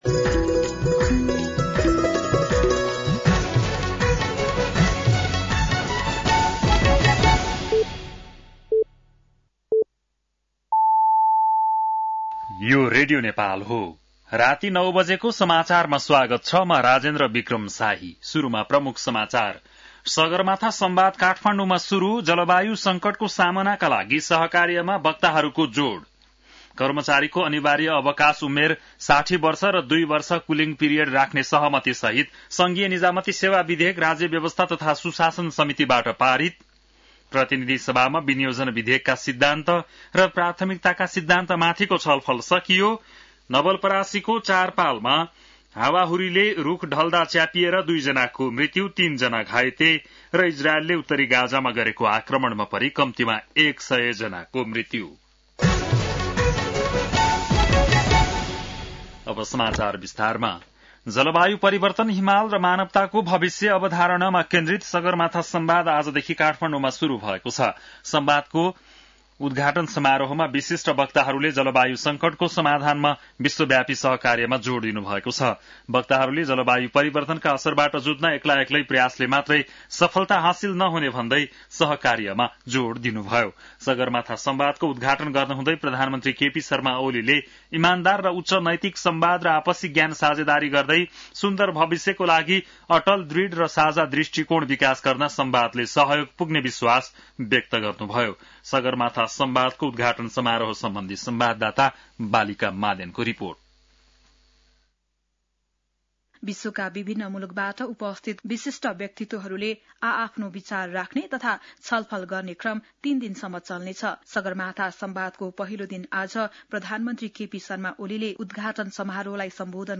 बेलुकी ९ बजेको नेपाली समाचार : २ जेठ , २०८२